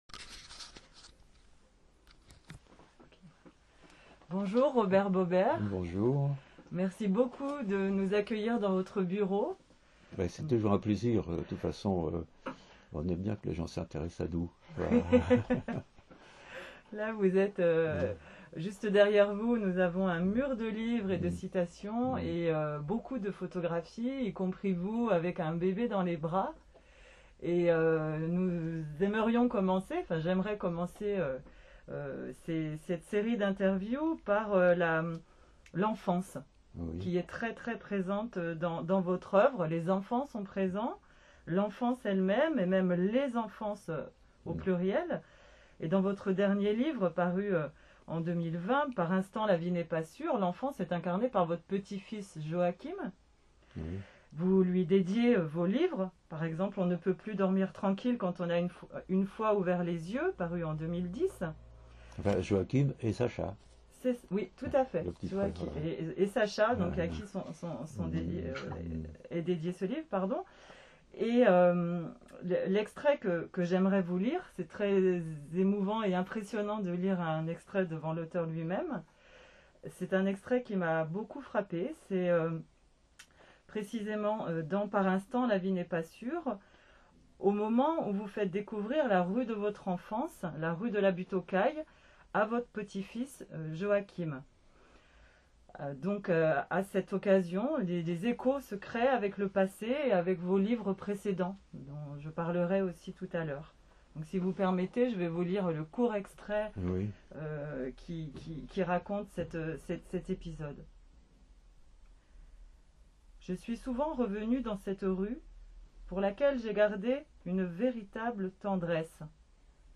Elle vous offre sur ces pages quatre entretiens